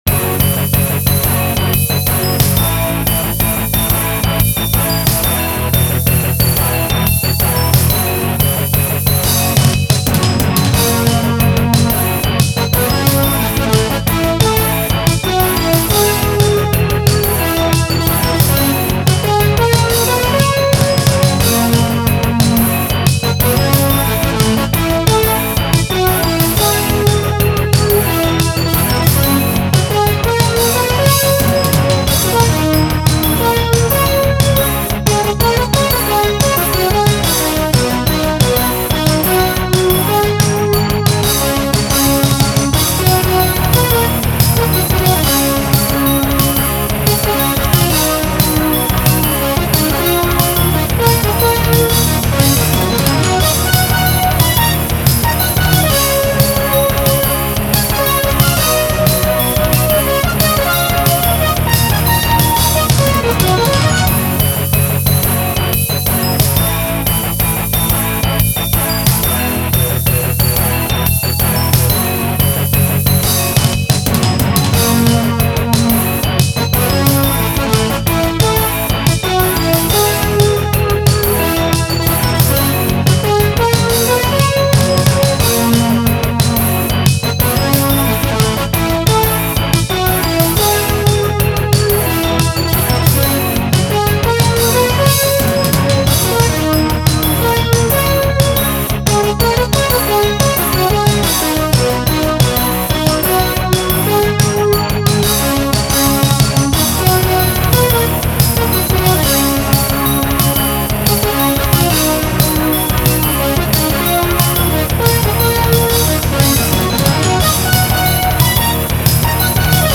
MIDIとFM音源が入り混じったカオス！